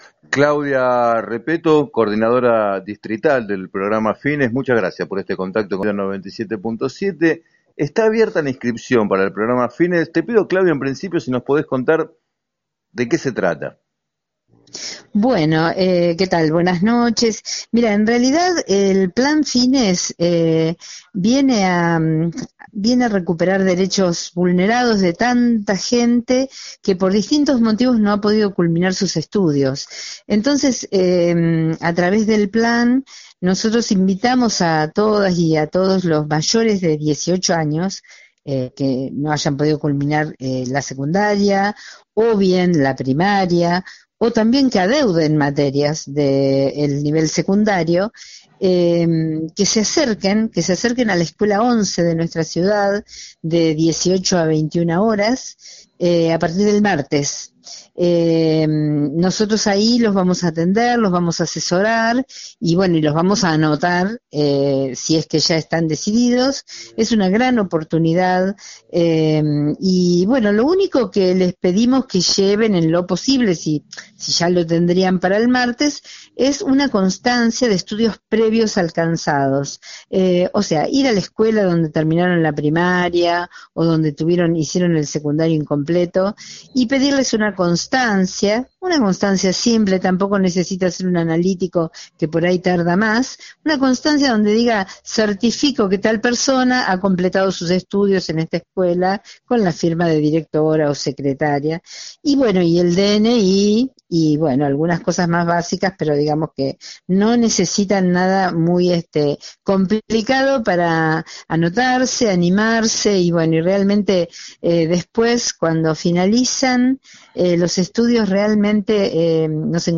En declaraciones al programa “7 a 9”